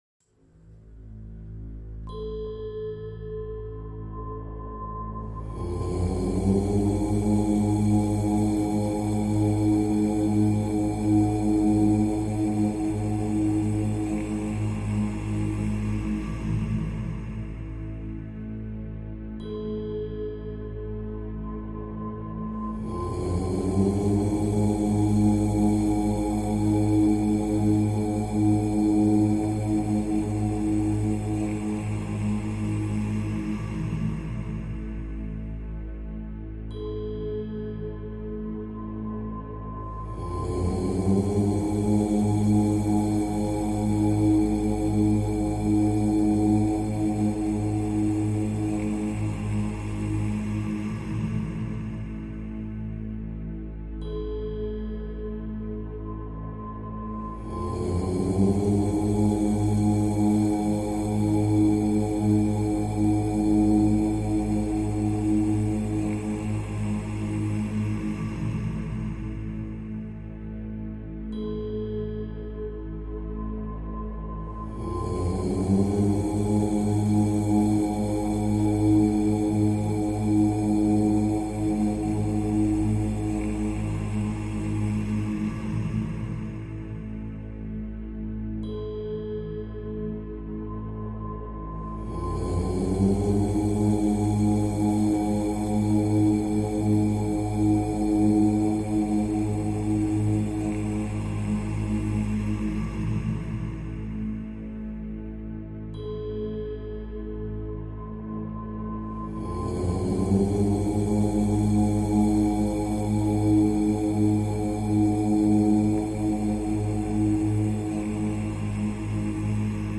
Le bol tibétain et le son OM
2020 CHANTS TIBÉTAINS audio closed https
bol-tibetain-le-son-OM.mp3